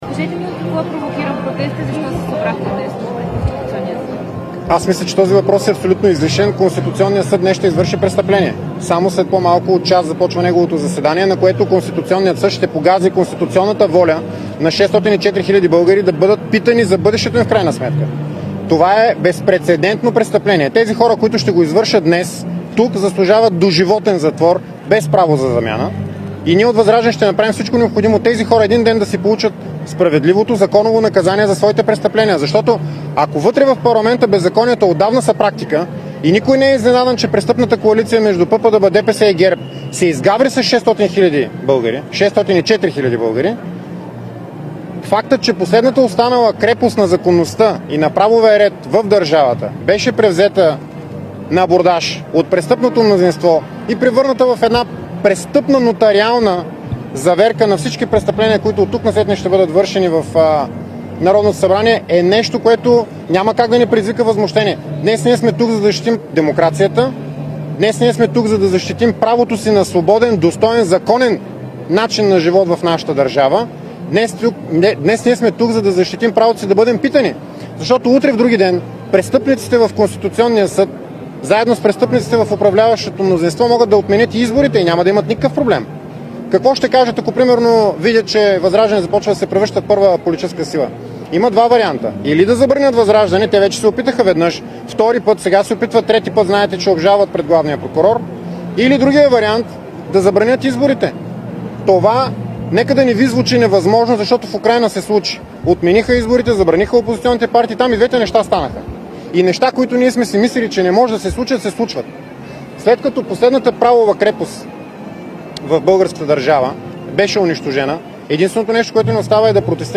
10.40 - Заседание на СОС.
Директно от мястото на събитието